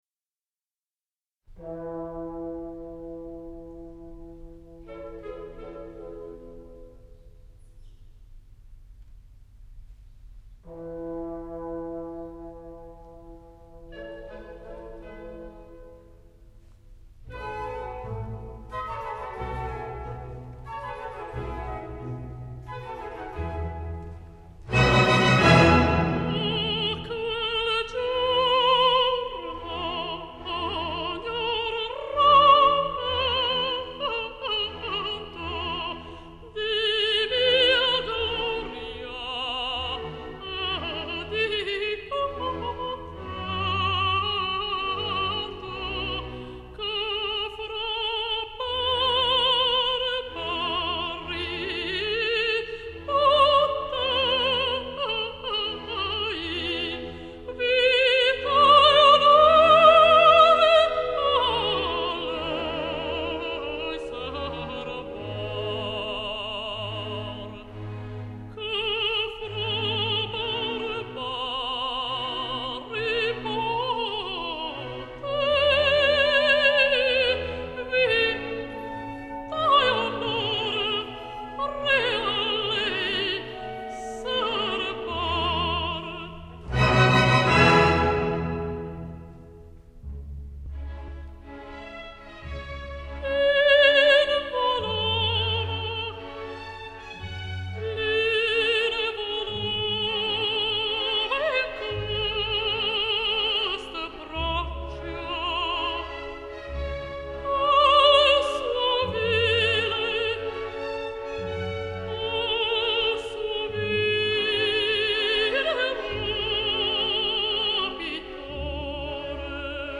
Несколько записей прекрасной румынской певицы меццо-сопрано Елены Черней ( 1924-2000)